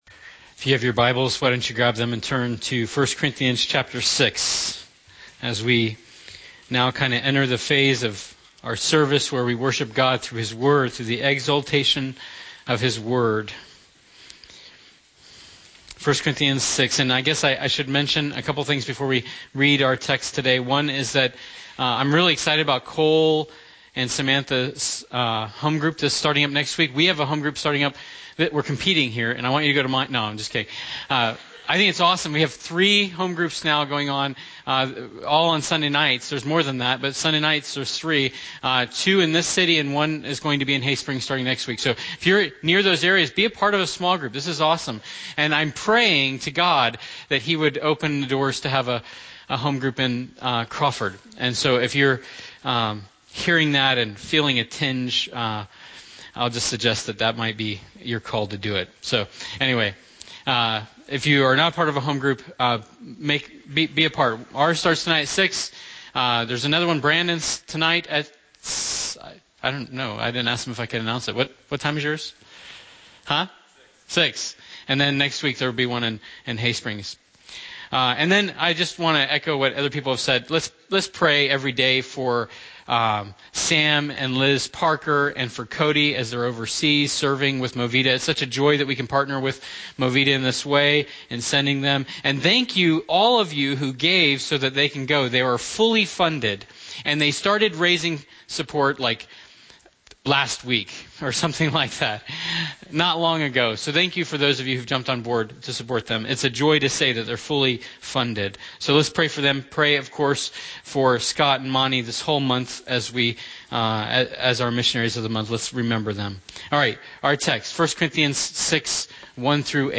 Sermons | Ridgeview Bible Church